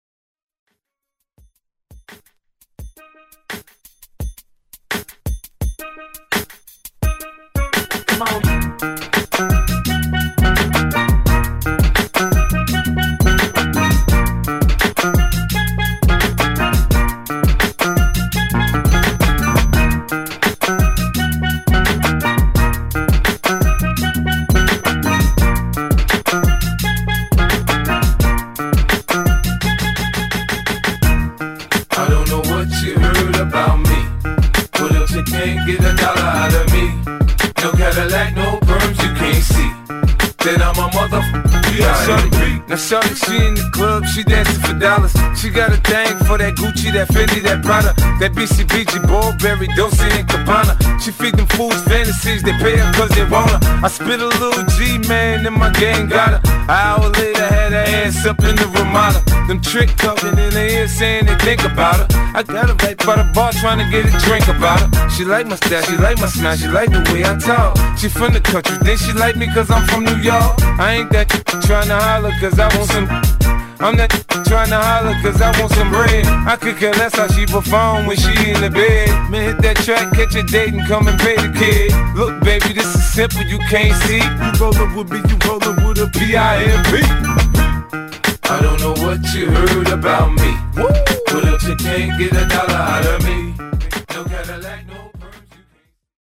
Genre: HIPHOP Version: Clean BPM: 85 Time